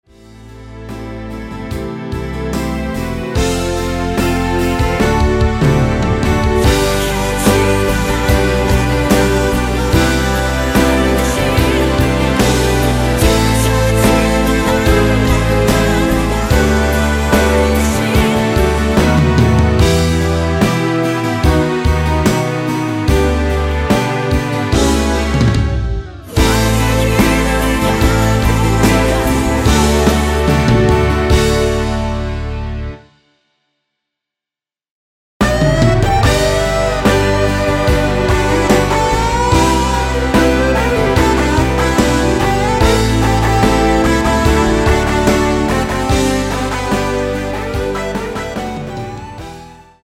원키 코러스 포함된 MR입니다.